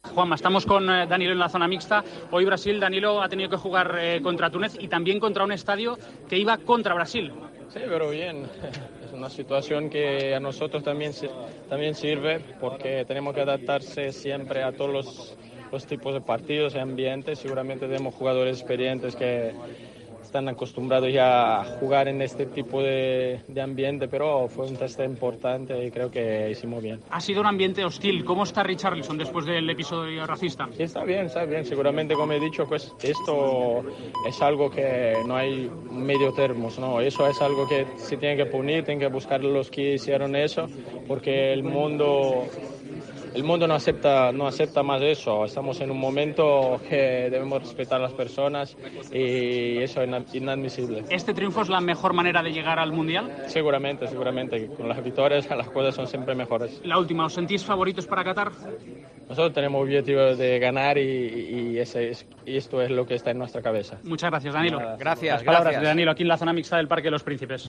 AUDIO: El exfutbolista del Real Madrid ha estado en El Partidazo de COPE tras el partido de Brasil ante Túnez.